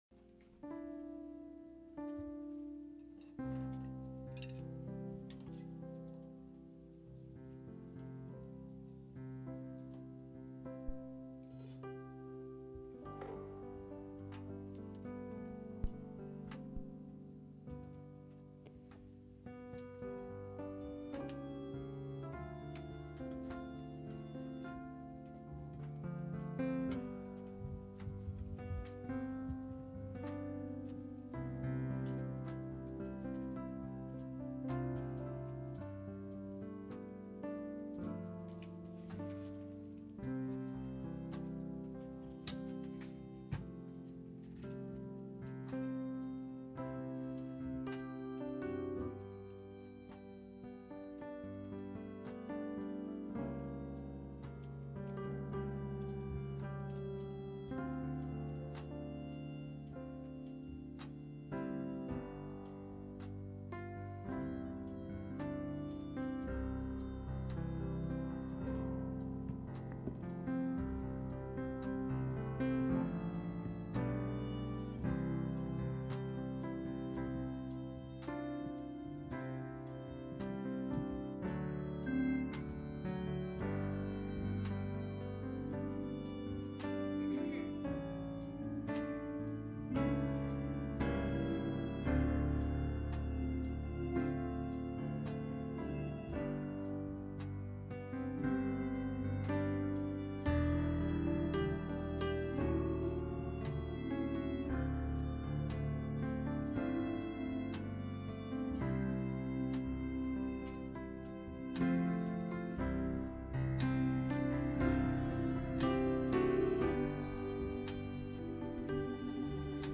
Pastor Passage: Hosea 10:12, Psalm 34:18 Service Type: Sunday Morning %todo_render% « Desperation Brings The Supernatural Revelation Chapter 11